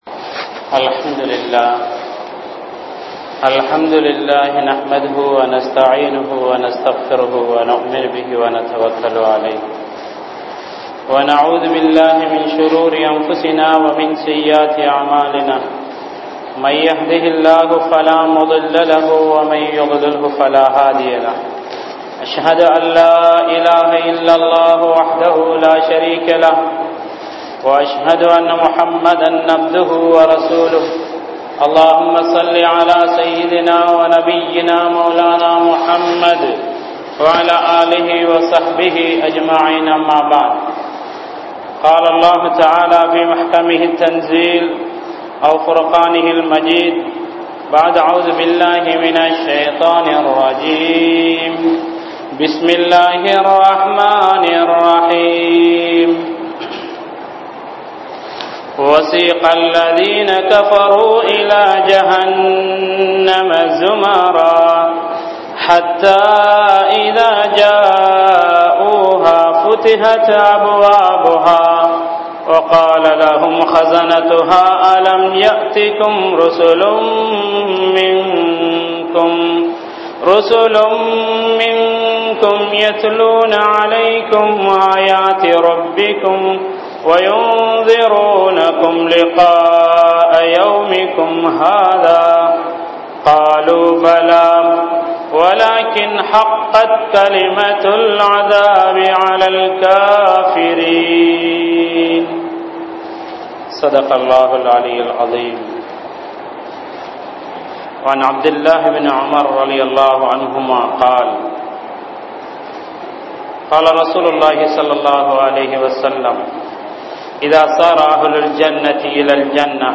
Naraham | Audio Bayans | All Ceylon Muslim Youth Community | Addalaichenai
New Jumua Masjith